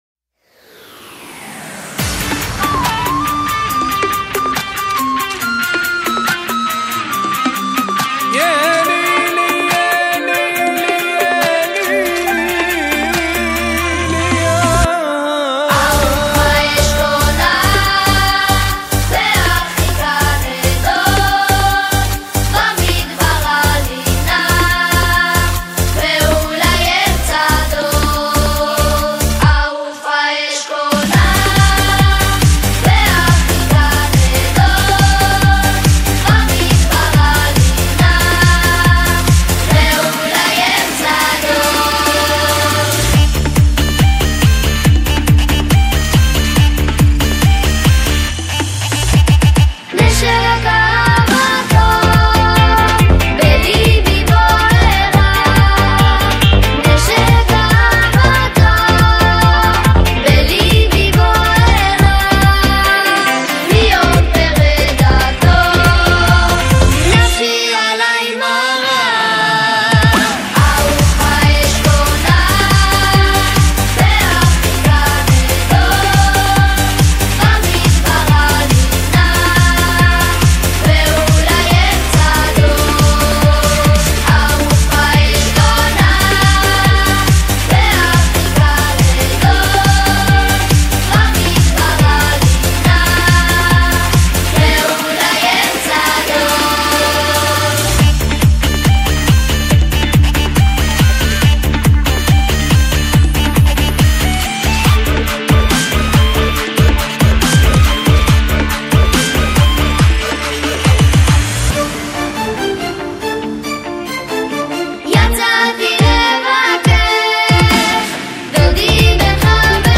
הרכב הילדים
התוצאה הקצבית והשמחה לפניכם!